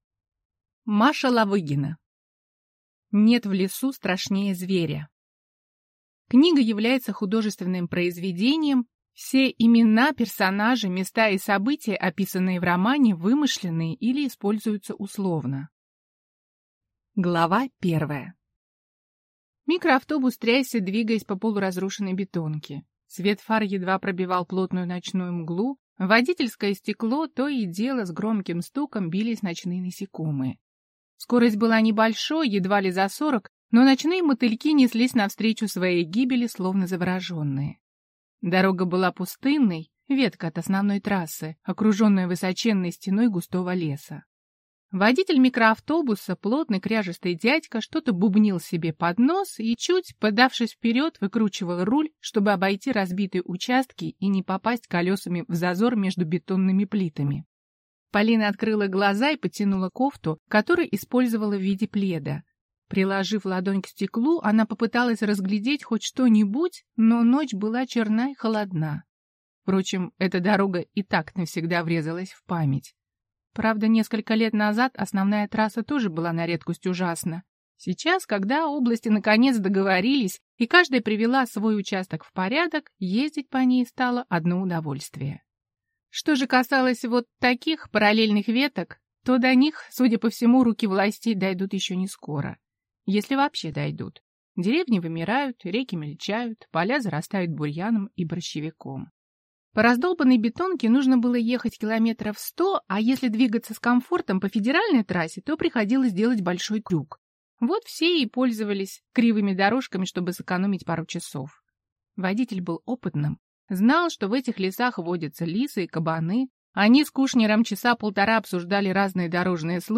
Аудиокнига Нет в лесу страшнее зверя | Библиотека аудиокниг